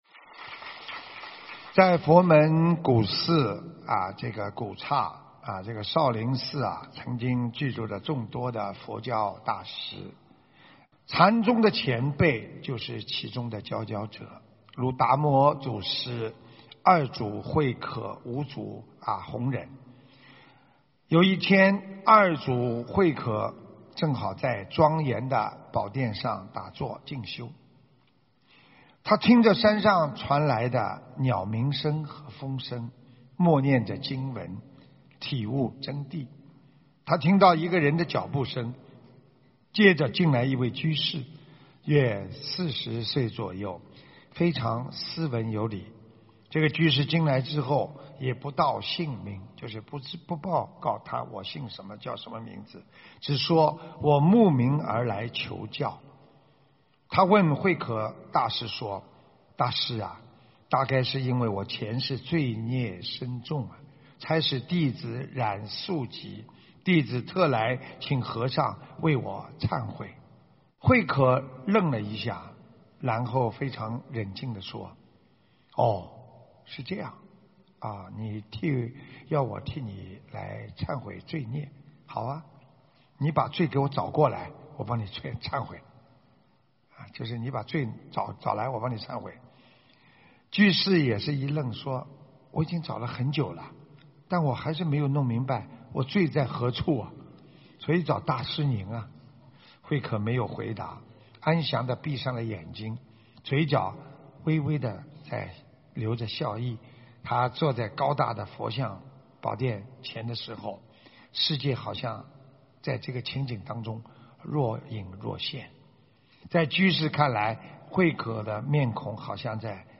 音频：【心安定则一切无碍】澳大利亚・布里斯本《世界佛友见面会》开示 2019年6月7日 （更新）